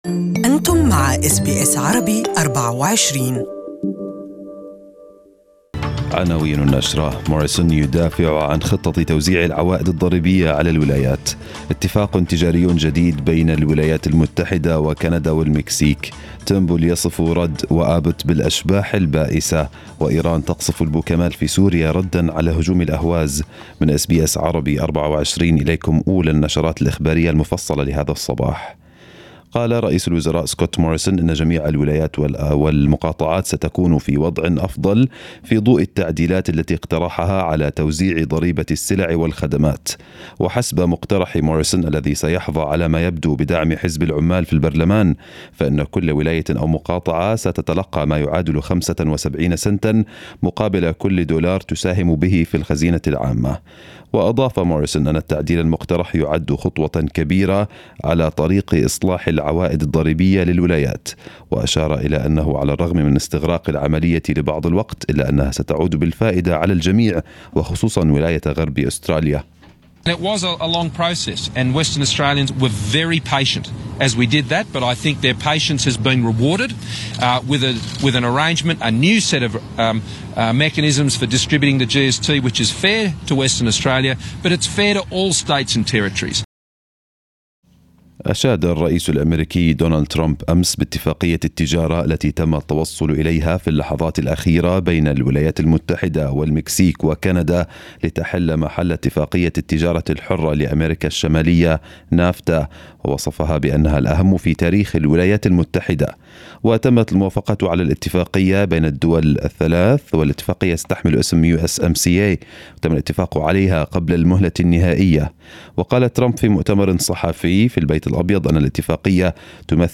News bulletin in Arabic